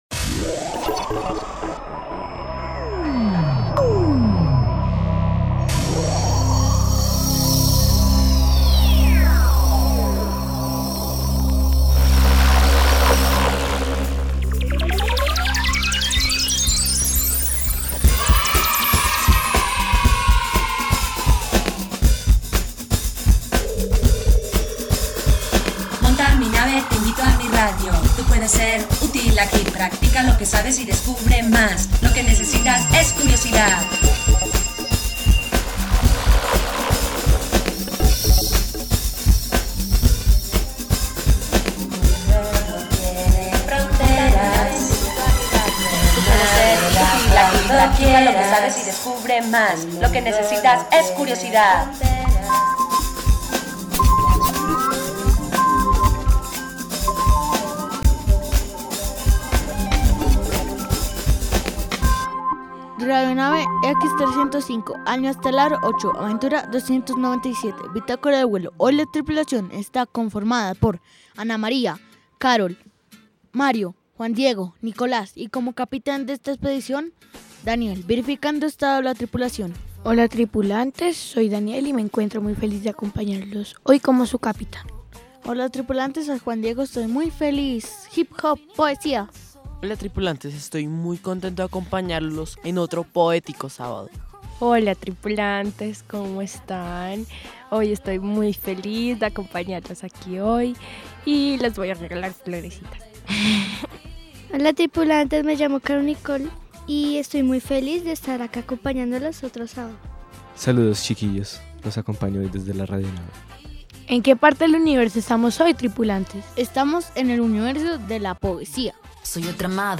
The program concludes with Colombian hip-hop performances and a call for personal expression through music